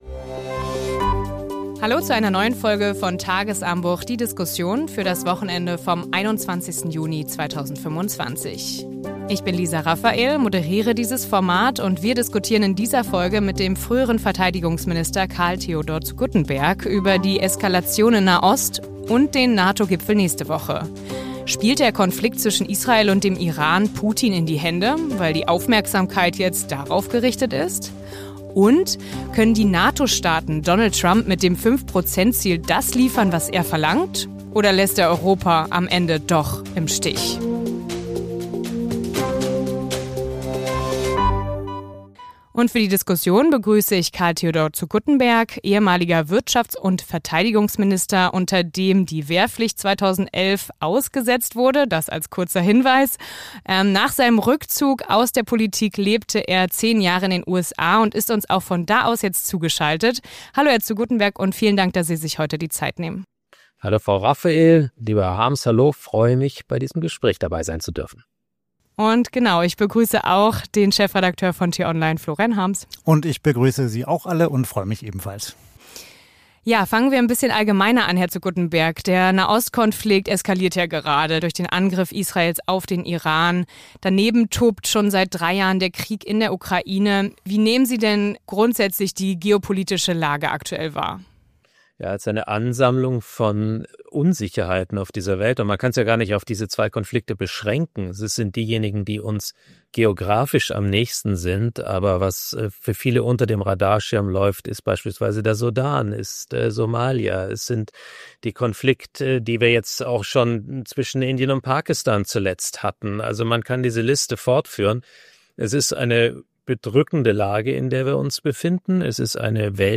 In dieser Folge analysiert der ehemalige Verteidigungsminister Karl-Theodor zu Guttenberg